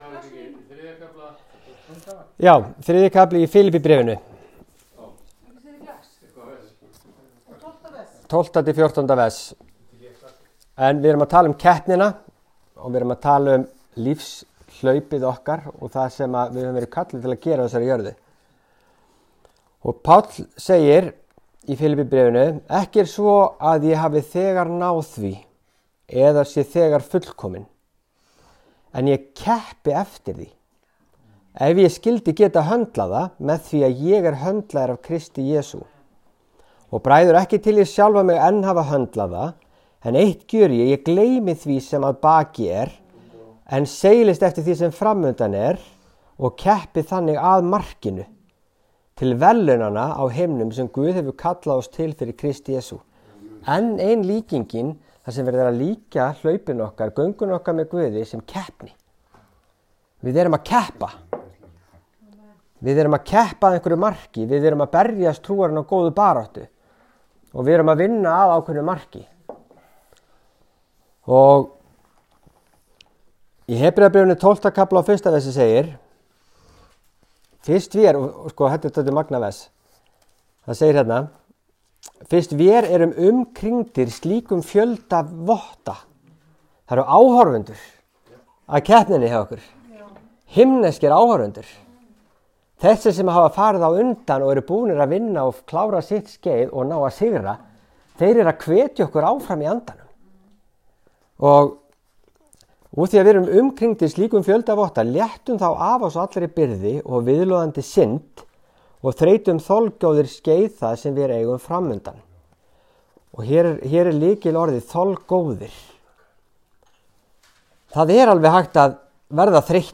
Kennslu síðustu viku getur þú hlustað á hér að ofan en hún var uppörvun til okkar allra að gefast ekki upp og halda áfram.